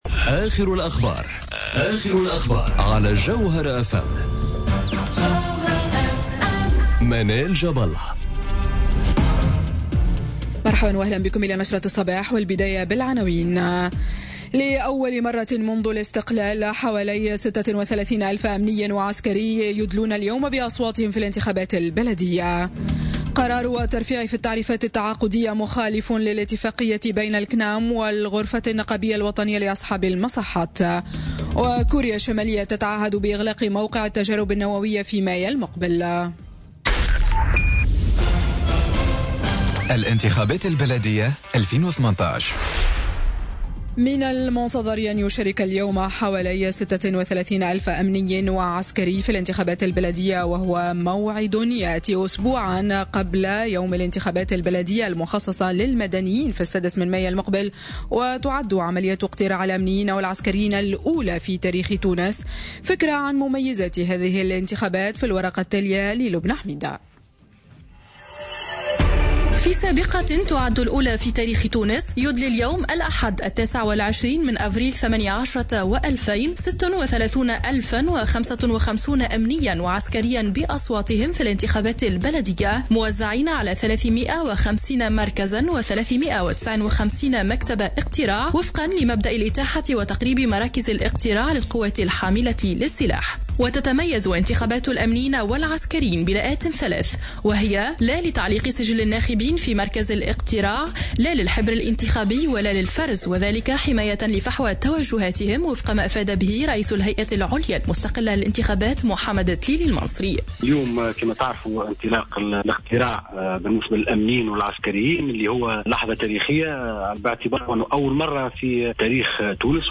نشرة أخبار السابعة صباحا ليوم الأحد 29 أفريل 2018